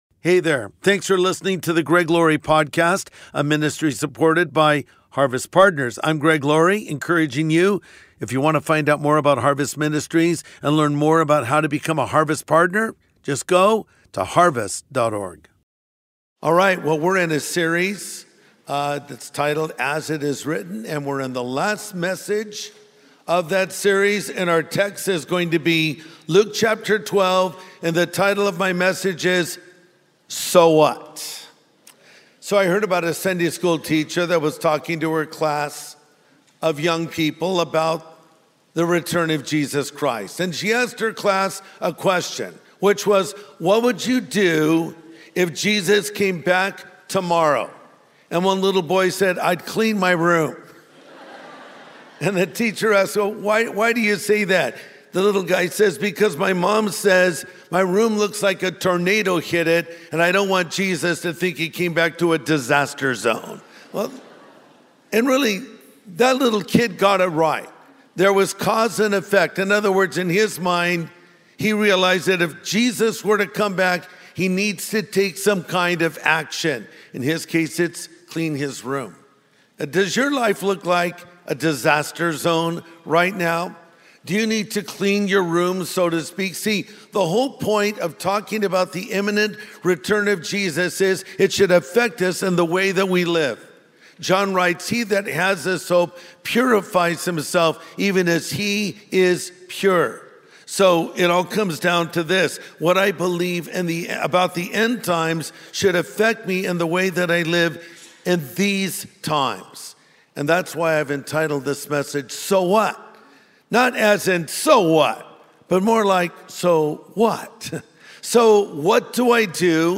How should we live in light of what we know about the end times? Pastor Greg Laurie shares the answer as we wrap up our series we're calling "The Future Is Written."